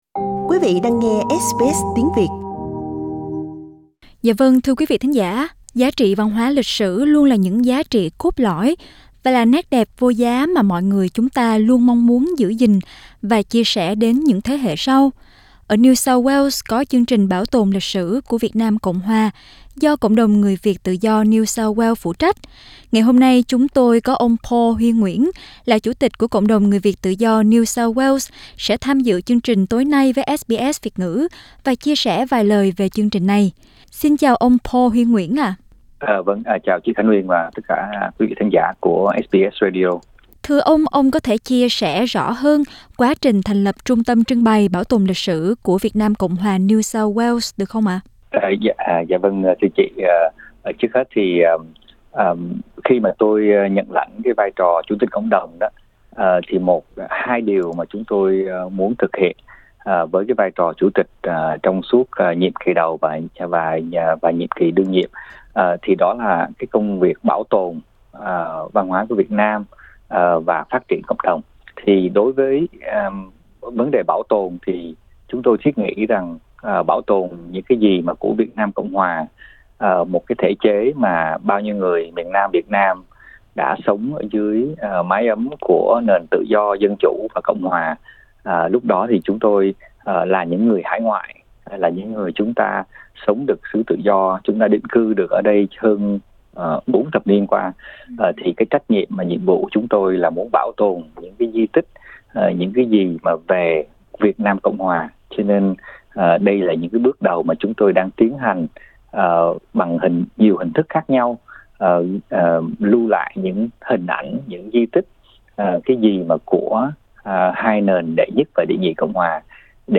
Mời quý thính giả bấm vào phần audio để nghe toàn bộ nội dung phỏng vấn